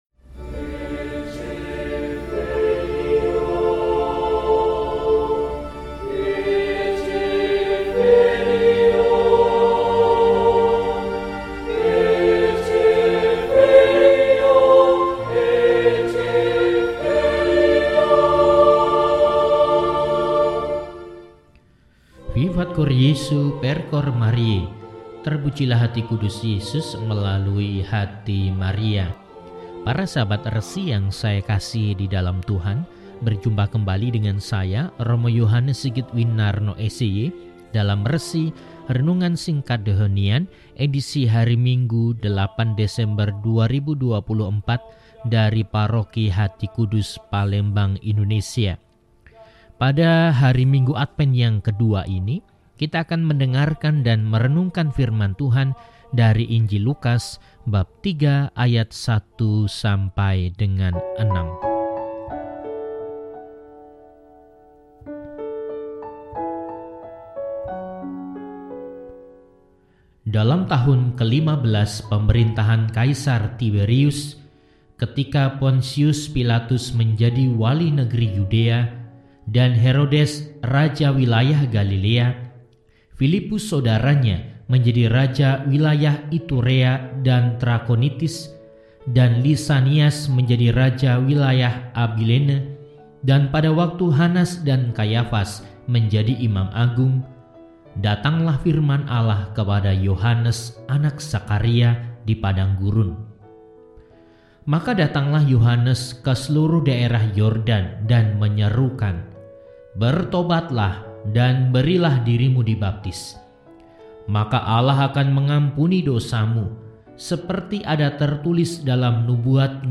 Minggu, 08 Desember 2024 – Hari Minggu Adven II – RESI (Renungan Singkat) DEHONIAN